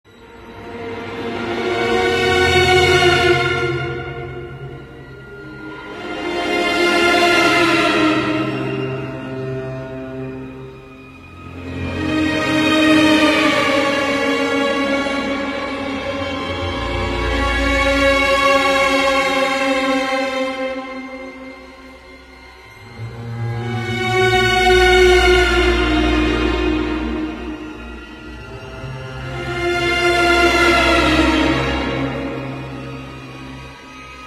Listen & Download New Tiktok & Reel Horror sound Ringtone.